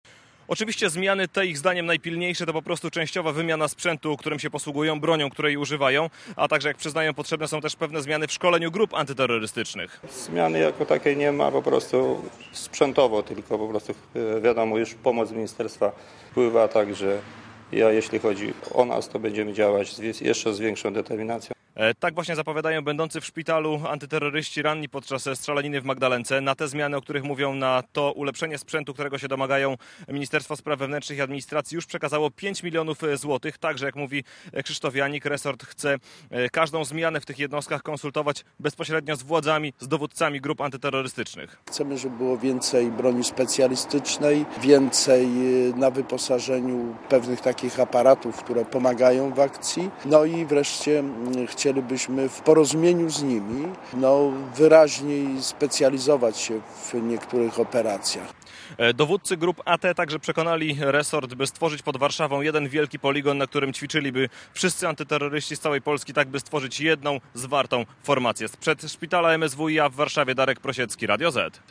Posłuchaj reportera Radia Zet (0.6 MB)